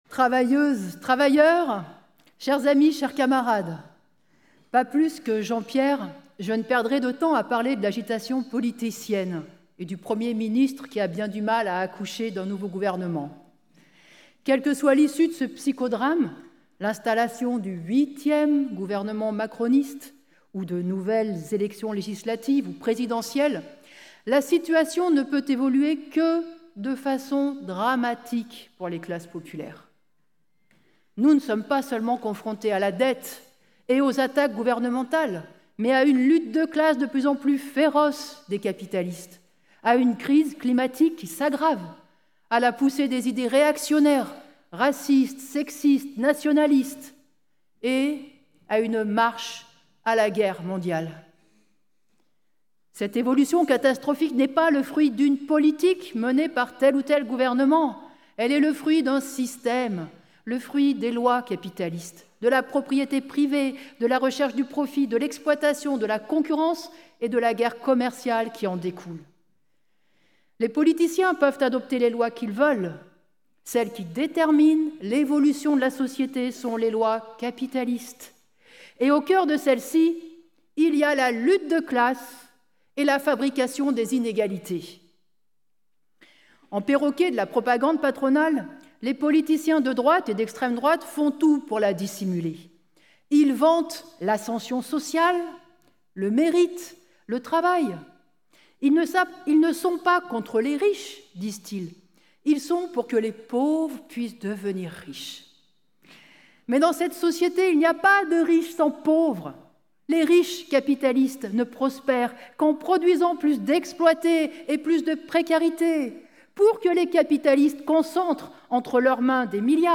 Meeting du 27 septembre 2025 à Paris : Discours de Nathalie Arthaud